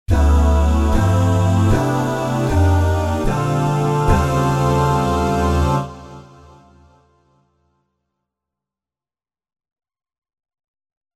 Dahs Guys demo =3-D03.mp3